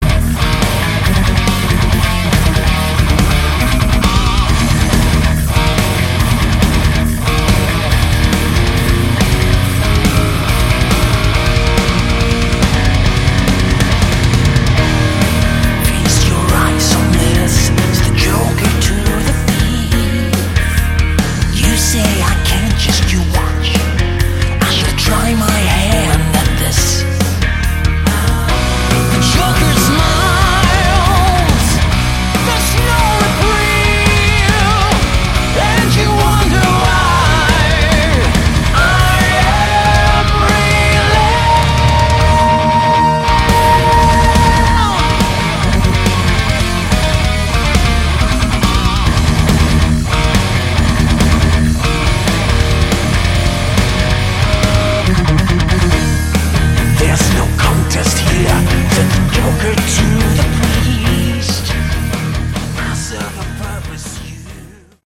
Category: Melodic Prog Metal
guitars
bass
drums
vocals